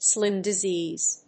アクセントslím disèase